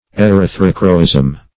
Search Result for " erythrochroism" : The Collaborative International Dictionary of English v.0.48: Erythrochroism \E*ryth"ro*chro*ism\, n. [Gr.
erythrochroism.mp3